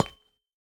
Minecraft Version Minecraft Version snapshot Latest Release | Latest Snapshot snapshot / assets / minecraft / sounds / block / copper / step4.ogg Compare With Compare With Latest Release | Latest Snapshot
step4.ogg